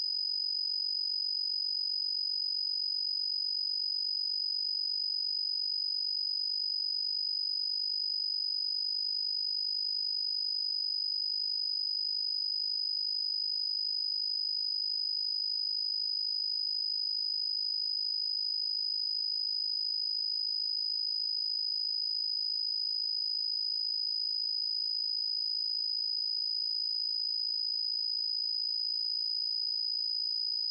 IMD試験 21.9MB